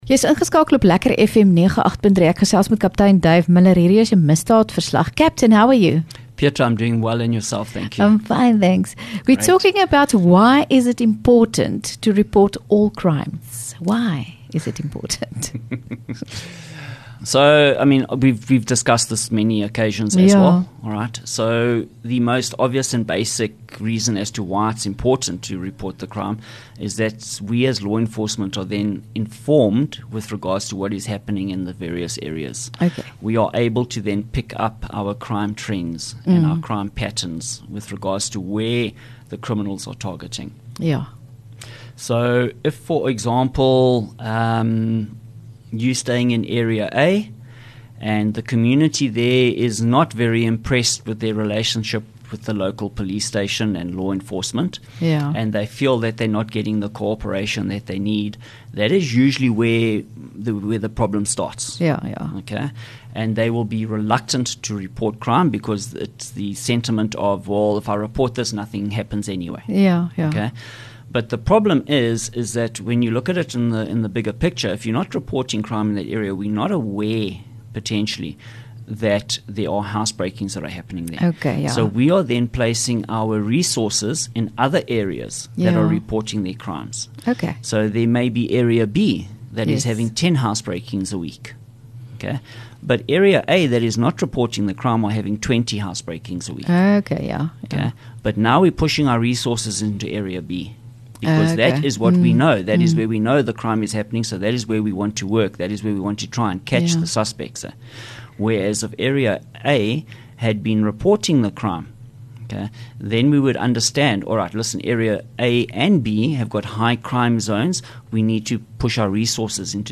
LEKKER FM | Onderhoude 8 Aug Misdaadverslag